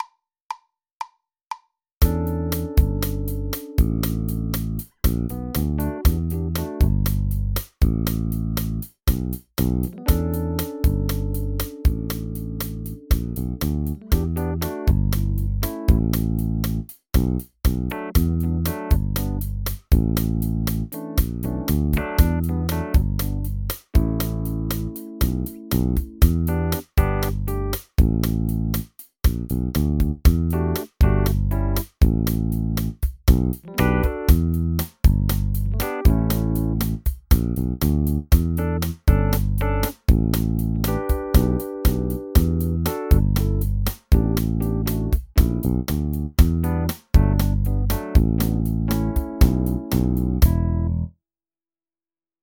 Backing track
A fun way to practice improvising is to play along with a recording of the rhythm section.
Solo-Section-Practice-Background-Jumbo-Shrimp.mp3